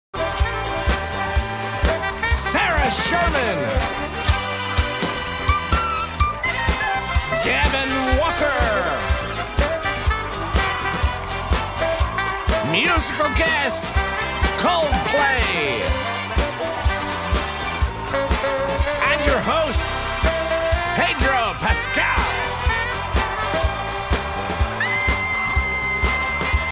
from the SNL livestream: pedro backstage before he walked out to do his opening monologue